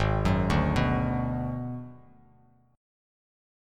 Abm7b5 chord